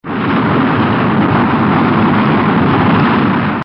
Шум пламени реактивного двигателя ракеты